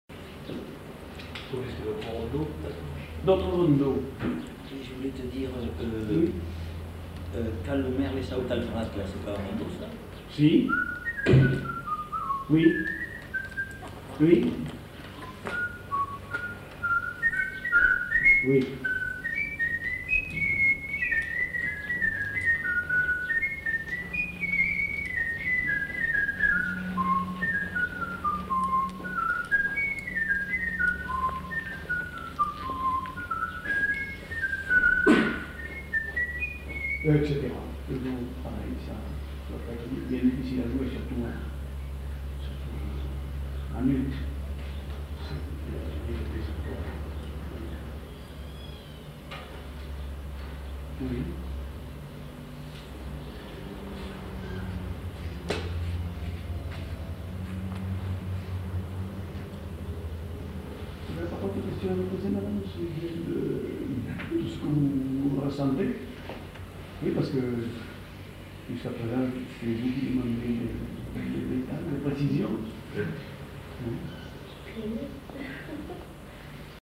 Aire culturelle : Agenais
Lieu : Foulayronnes
Genre : chant
Effectif : 1
Type de voix : voix d'homme
Production du son : sifflé
Danse : rondeau
Ecouter-voir : archives sonores en ligne